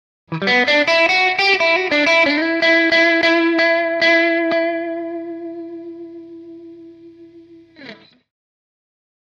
Electric Guitar
Blues Guitar - Short Solo 5